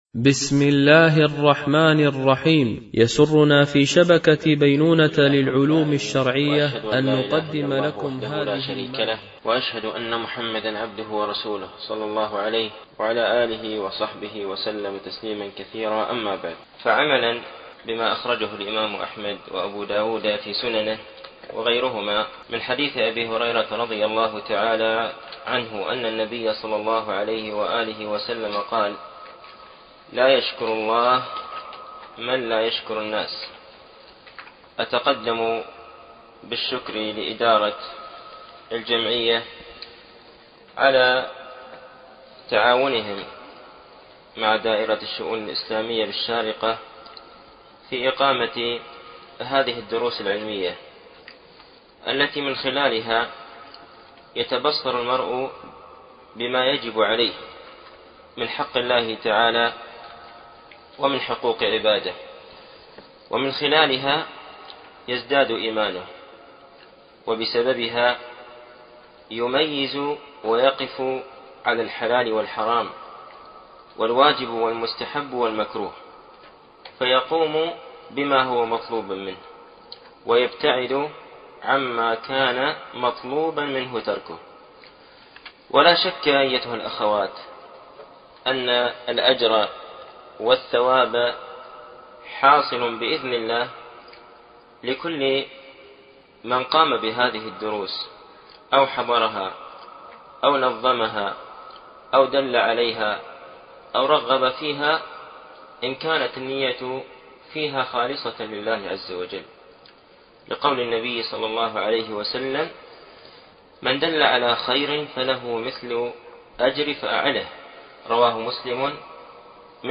شرح كتاب فضل الاسلام ـ الدرس الاول